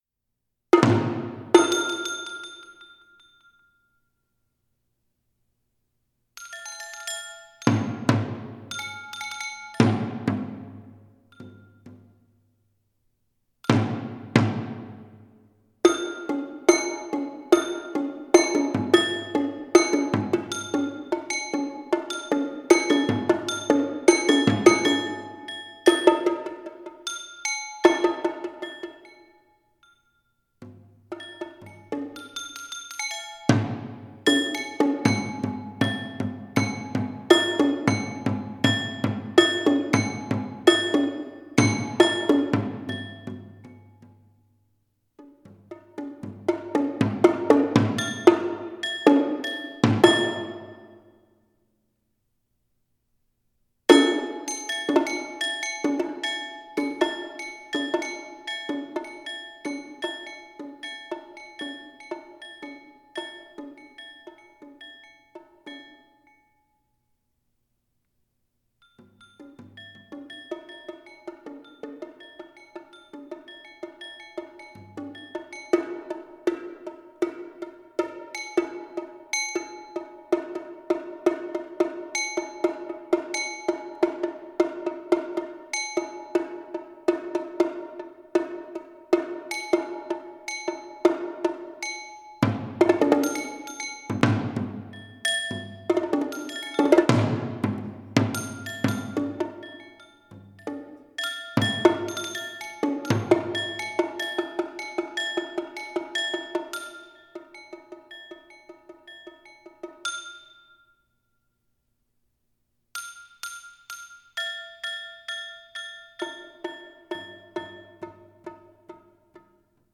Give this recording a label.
Voicing: Multi-Percussion Unaccompanied